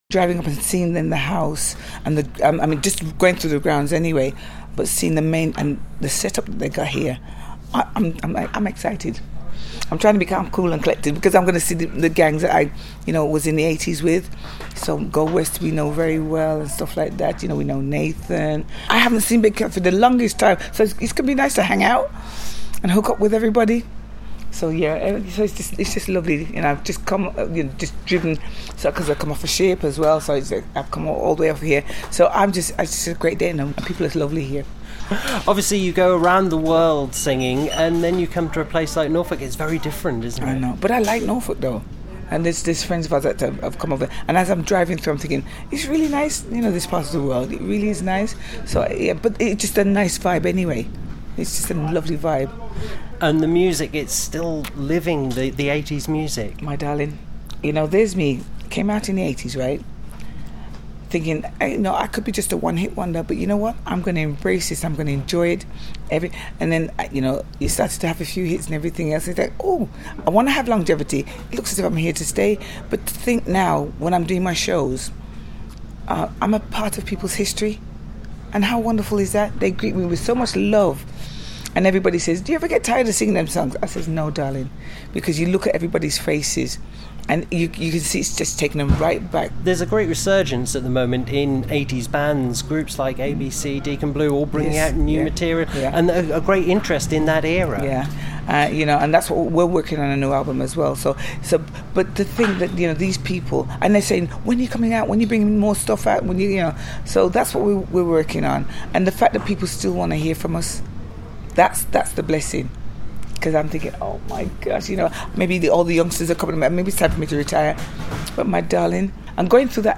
Thousands of people flocked to Holkham Hall for a celebration of the 80’s – with a host of top names taking part.